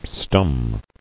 [stum]